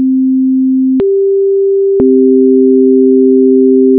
cfifth.wav